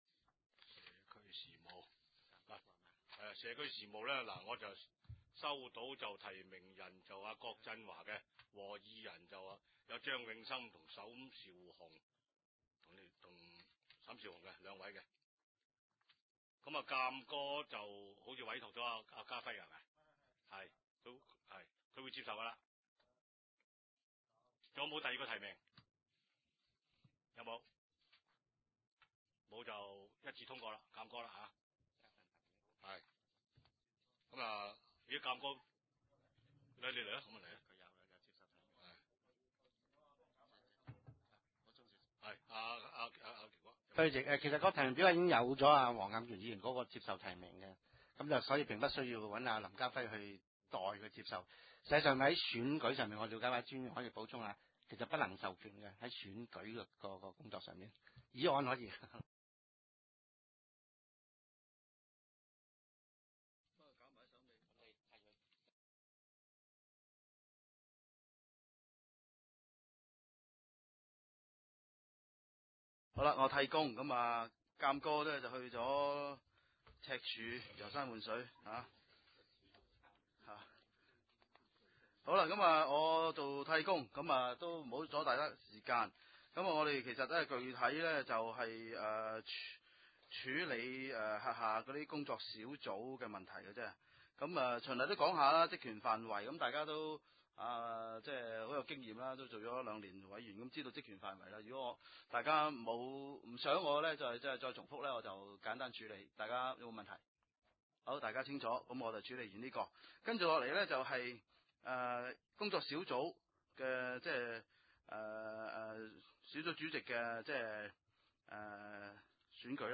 區 事務委員會特別會議議程
深水埗區議會會議室